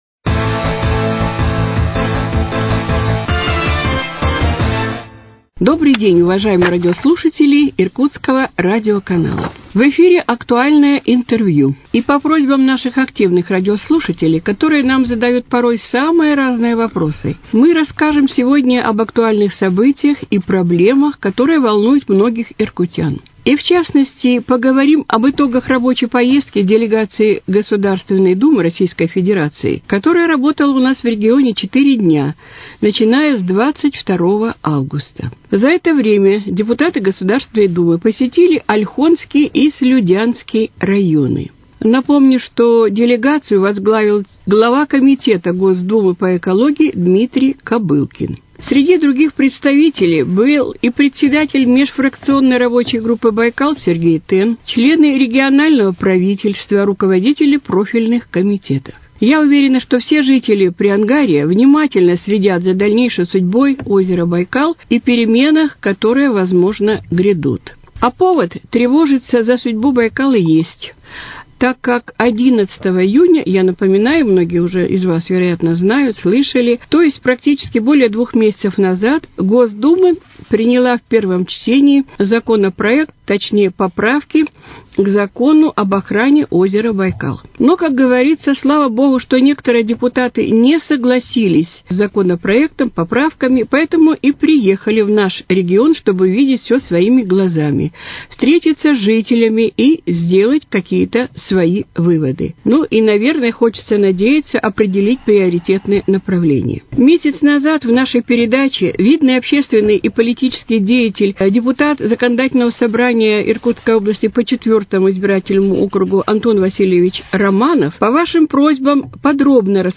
Актуальное интервью: Депутат Заксобрания Иркутской области Антон Романов отвечает на вопросы слушателей
На вопросы радиослушателей отвечает депутат Законодательного собрания Иркутской области Антон Романов.